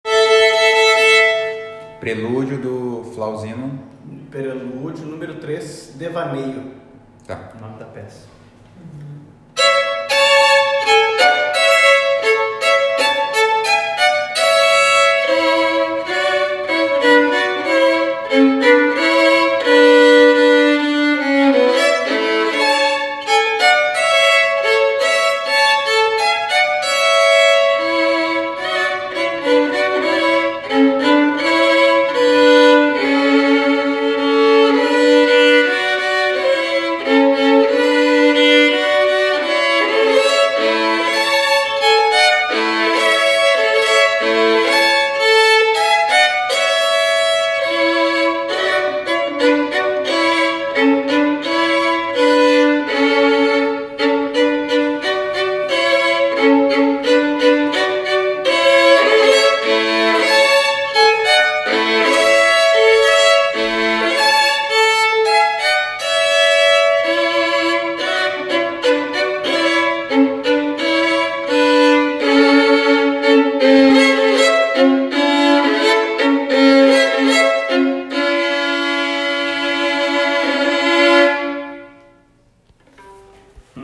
Clique no play e ouça a introdução da obra musical “Devaneio”, de autoria do violinista Flausino Valle